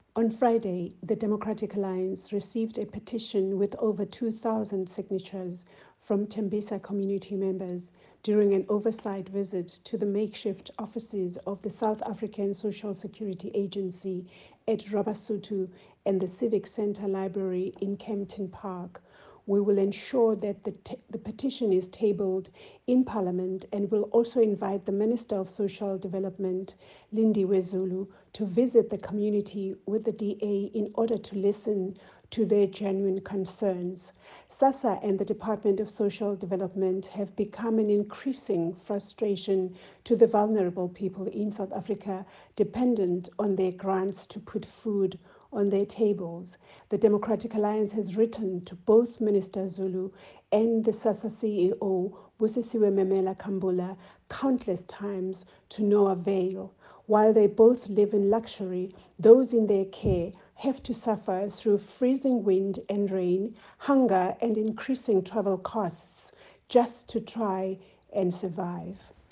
soundbite by Bridget Masango MP, as well as attached pictures here, here, and here.